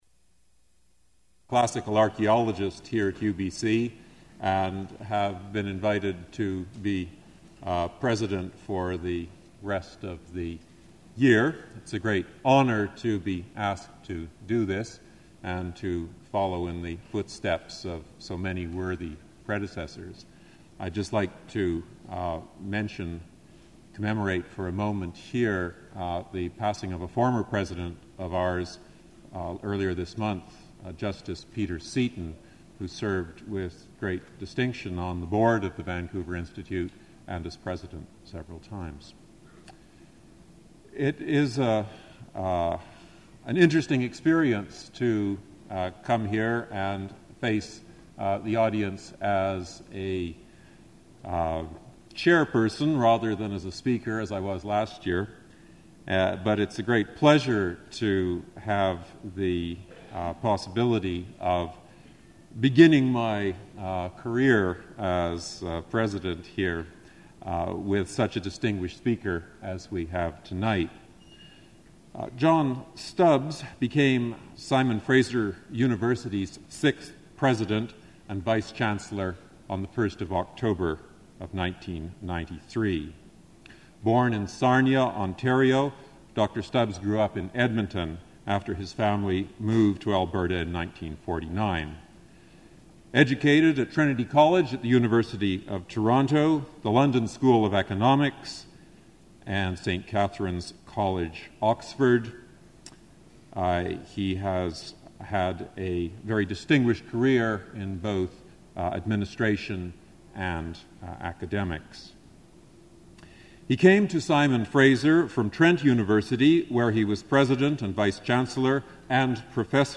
Original audio recording available in the University Archives (UBC AT 1920).